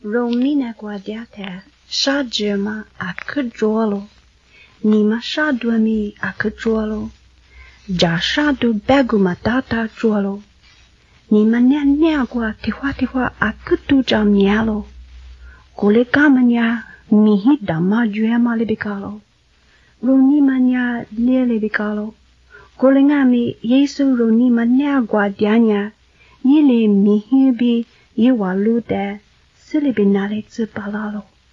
The affricates, retroflexes, and “apical vowels” strike me as Mandarin-like, even though this is definitely NOT Mandarin.